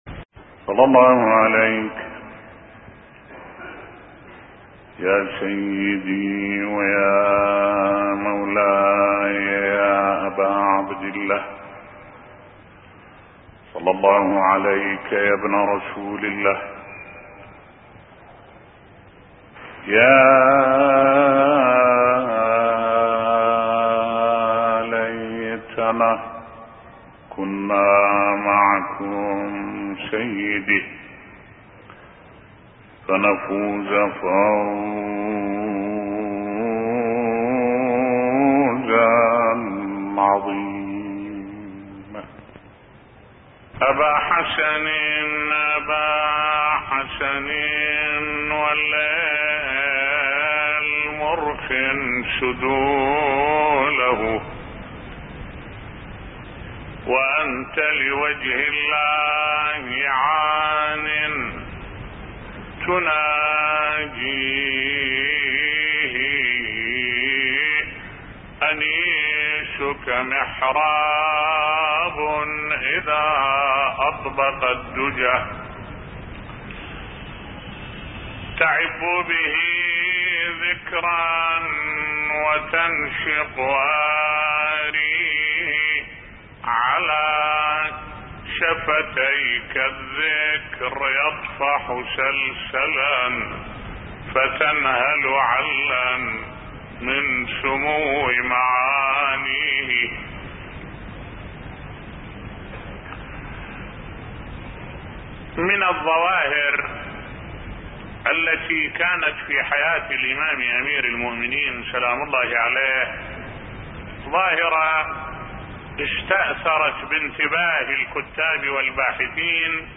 ملف صوتی حياة الإمام علي بن أبي طالب عليه السلام بصوت الشيخ الدكتور أحمد الوائلي